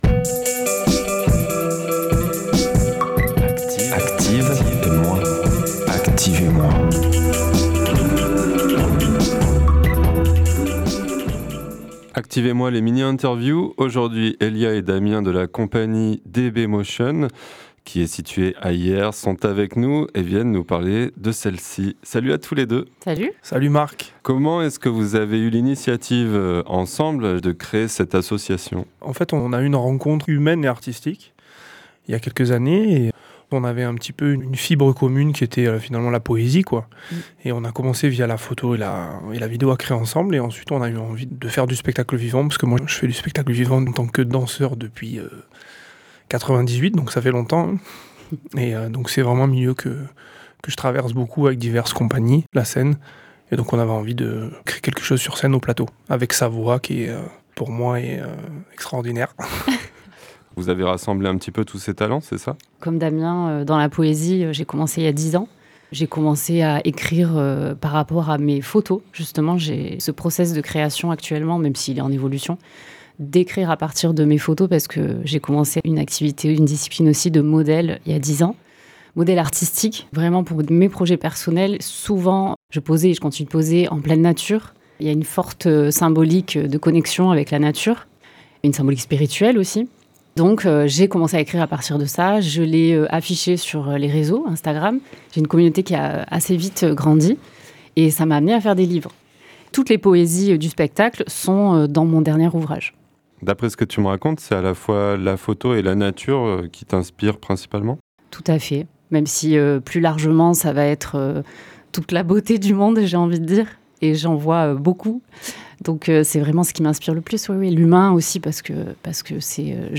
Interview avec la compagnie DB Motion autour de la création de leur spectacle onirique “N’arrête pas de rêver”, entre danse et poésie. Première présentation le vendredi 23 janvier.